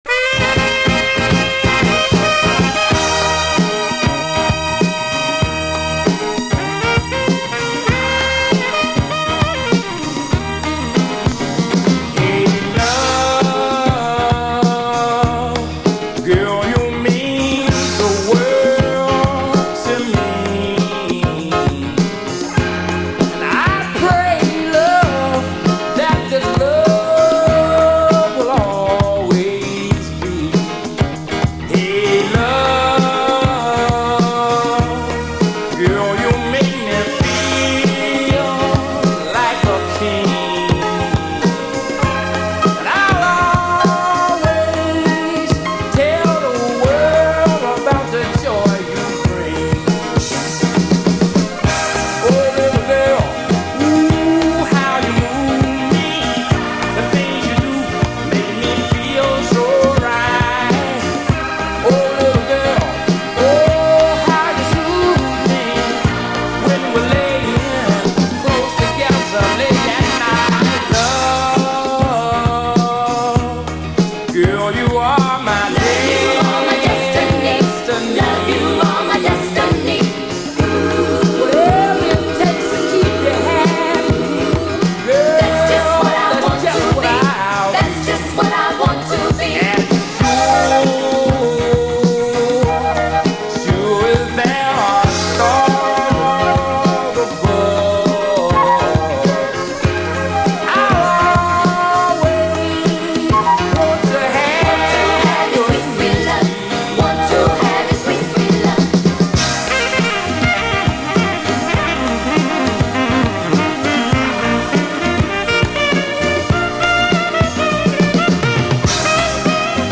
SOUL, 70's～ SOUL, 7INCH
シカゴ産モダン・ソウル45！溌剌とした女性コーラスも聴き所！